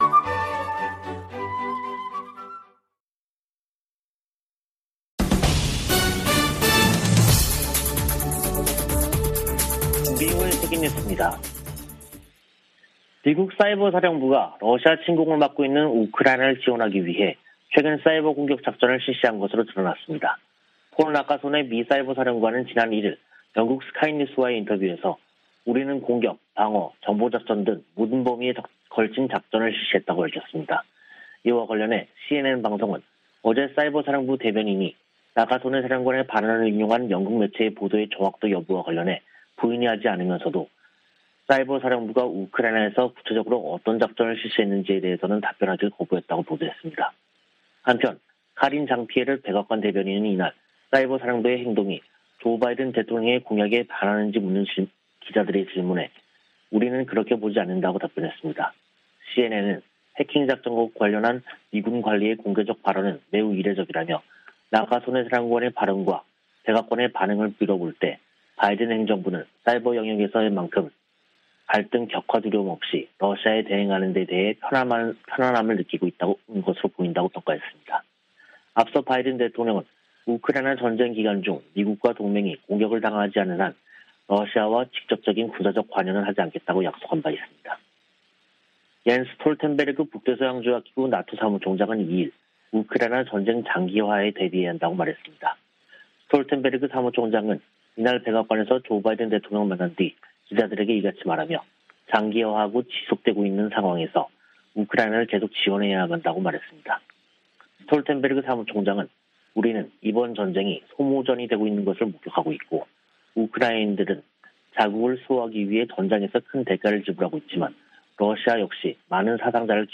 VOA 한국어 간판 뉴스 프로그램 '뉴스 투데이', 2022년 6월 3일 2부 방송입니다. 미국과 한국, 일본의 북핵 수석대표들은 북한의 7차 핵실험 준비 동향에 대해 긴밀한 공조를 통한 억제력 강화 등을 경고했습니다. 미 국무부는 2021년도 종교자유보고서에서 북한의 종교 탄압이 심각히 우려되고 있다고 지적했습니다. 웬디 셔먼 미 국무부 부장관은 북한에 추가 도발을 자제하고 대화의 길로 나올 것을 거듭 촉구했습니다.